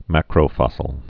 (măkrō-fŏsəl)